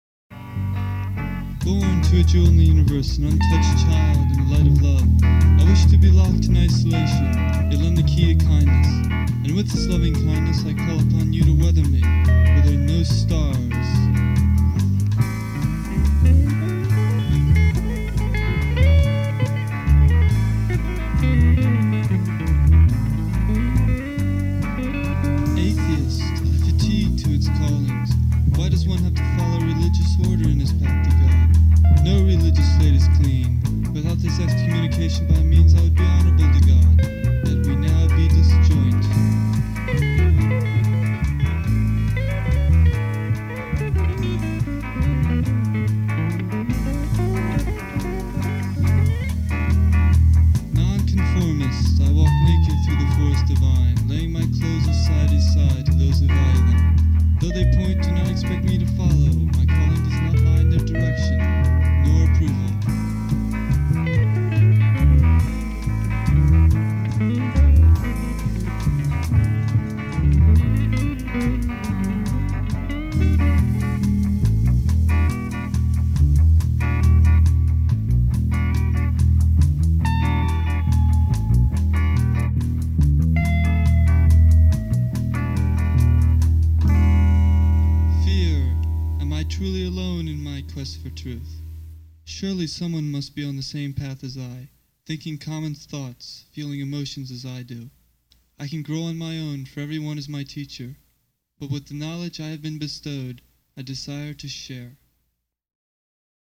poetic, instrumental tracks